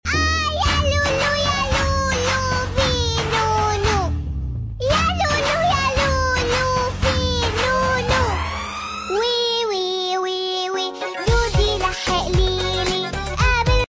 Dodi Dodi dum dum cute sound effects free download